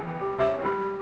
output / piano / 126-8.wav